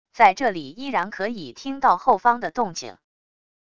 在这里依然可以听到后方的动静wav音频生成系统WAV Audio Player